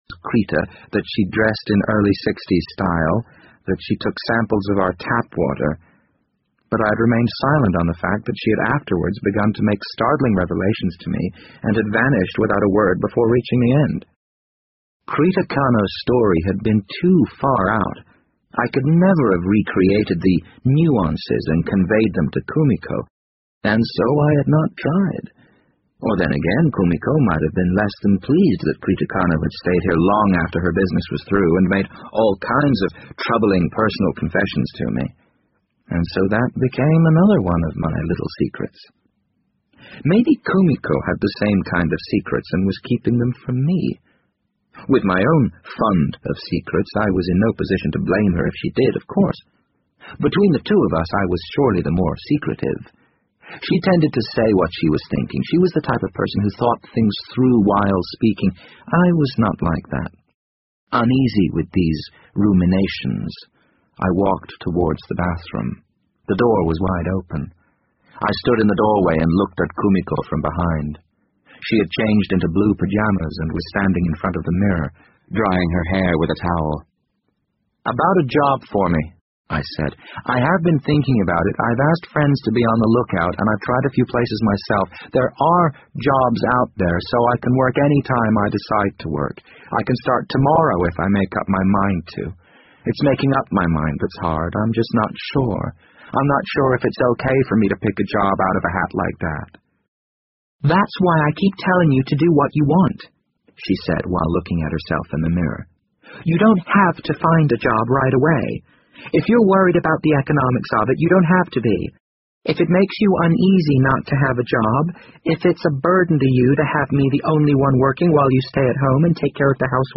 BBC英文广播剧在线听 The Wind Up Bird 61 听力文件下载—在线英语听力室